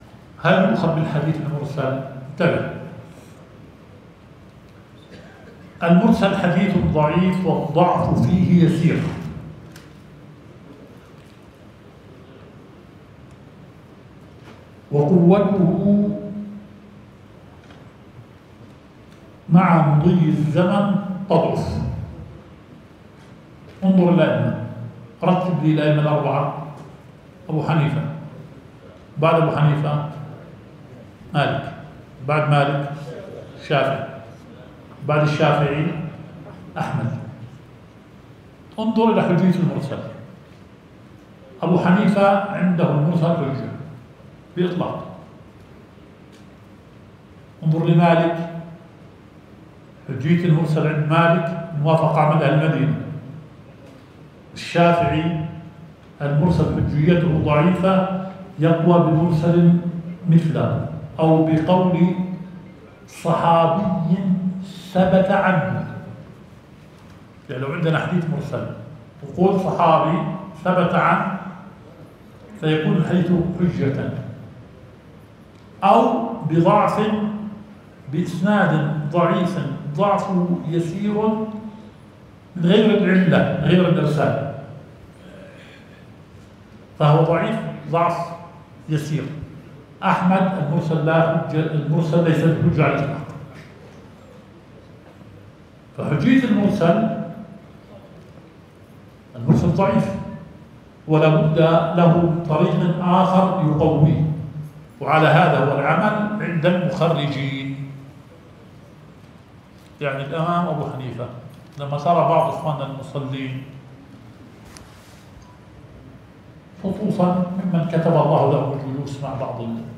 البث المباشر – لدرس شيخنا شرح صحيح مسلم